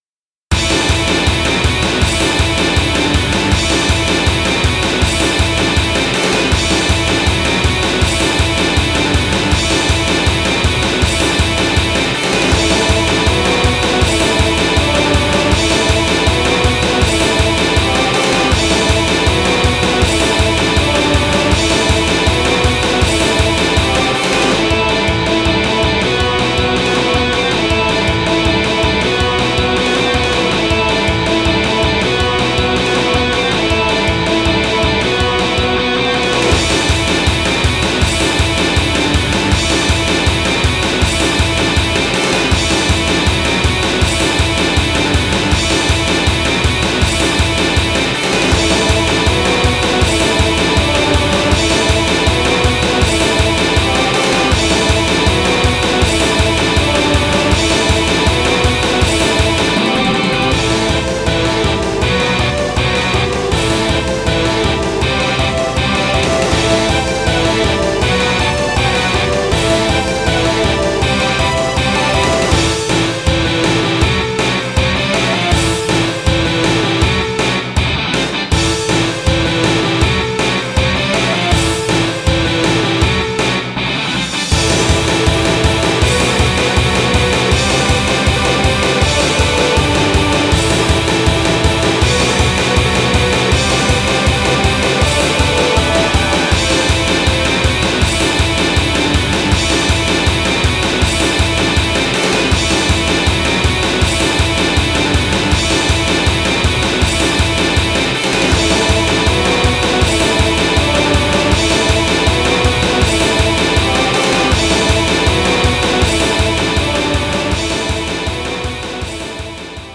メタル風戦闘曲。